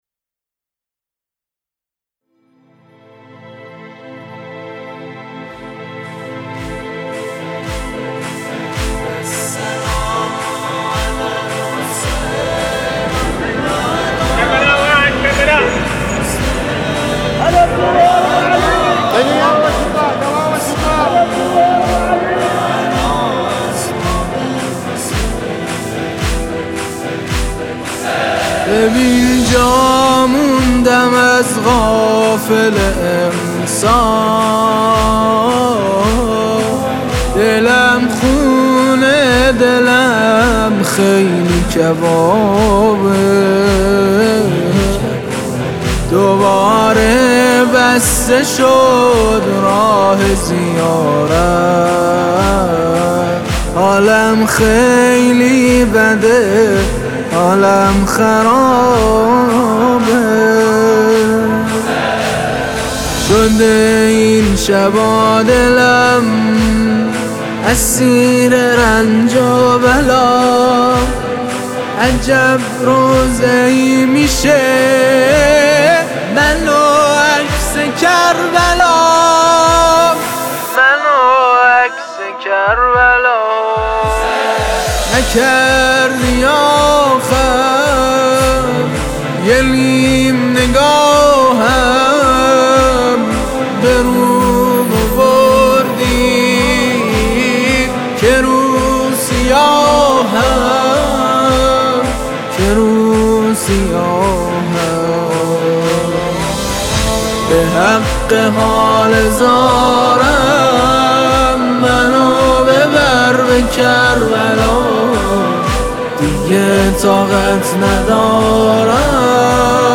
تهیه شده در استودیو نجوا